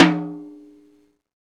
TOM XTOMHI1J.wav